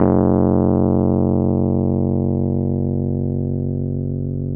RHODES-F#0.wav